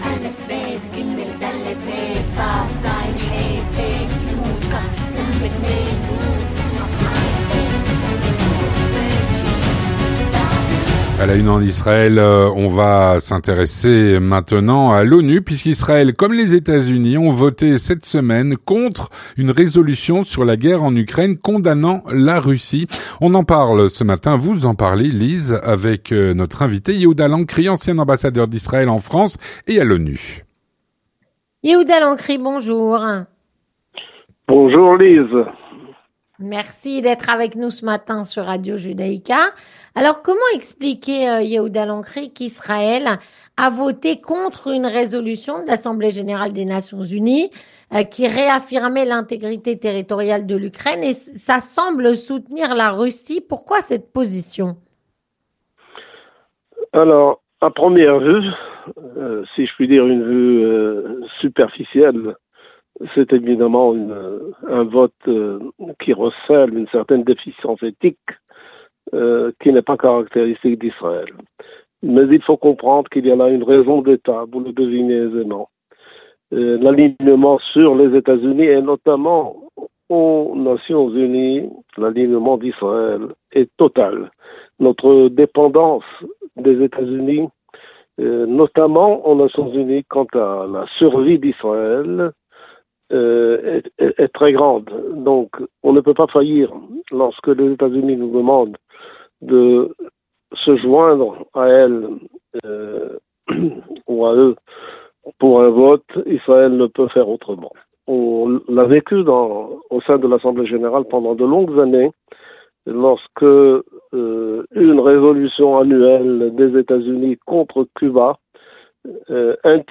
On en parle avec Yehuda Lancry, ancien ambassadeur d'Israël en France et à l’ONU.